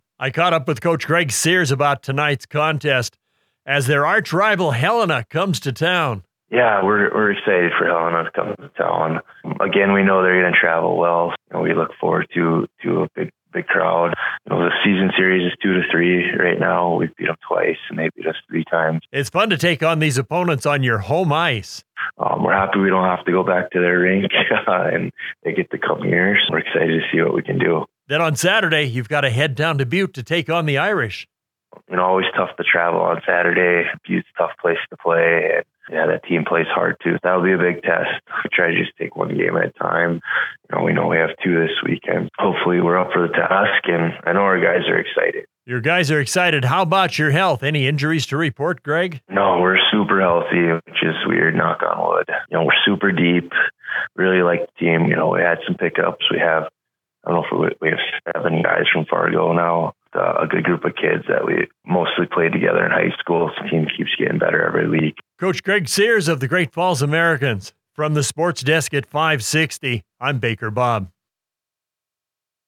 560AM KMON: Weekly Radio Interview